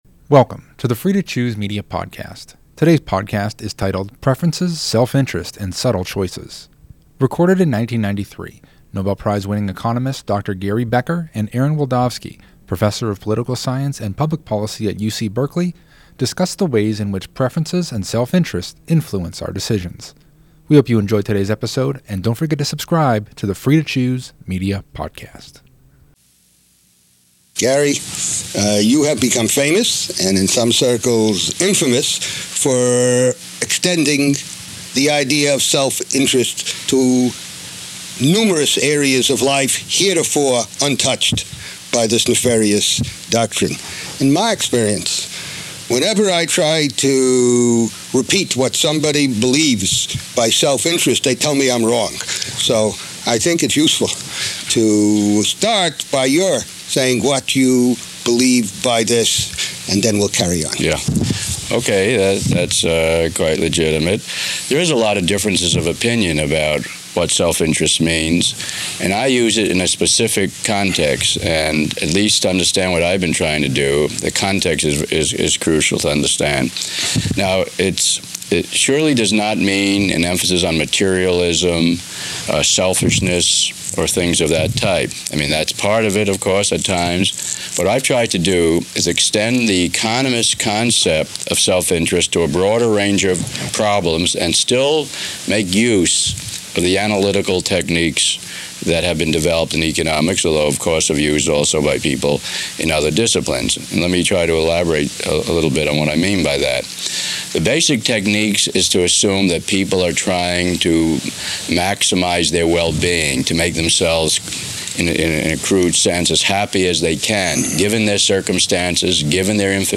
Recorded in 1993, Nobel Prize winning economist Dr. Gary Becker and Aaron Wildavsky, Professor of Political Science and Public Policy at UC Berkeley, discuss the ways in which preferences and self-interest influence our decisions.